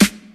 • Airy Rap Acoustic Snare Sample G# Key 150.wav
Royality free acoustic snare sound tuned to the G# note. Loudest frequency: 2166Hz
airy-rap-acoustic-snare-sample-g-sharp-key-150-5FZ.wav